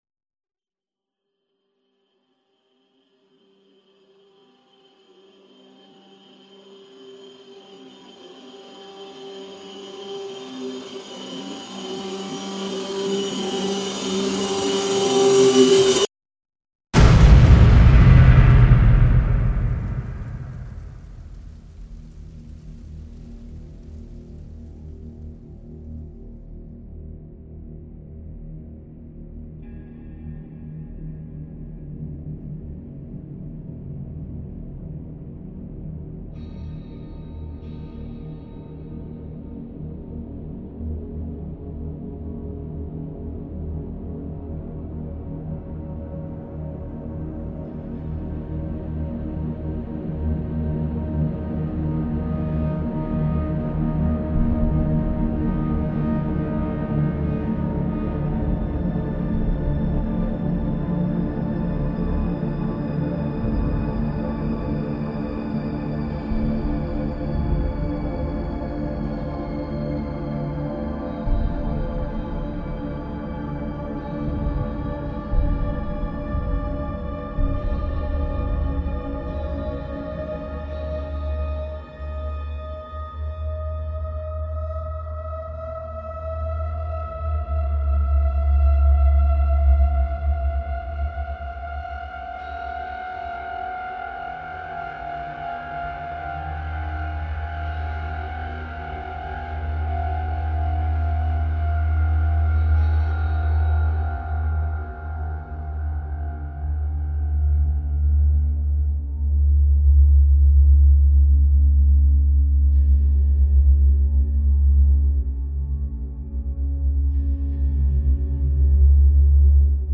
描述：基调|激越
Tag: 合成器